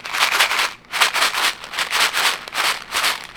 R - Foley 243.wav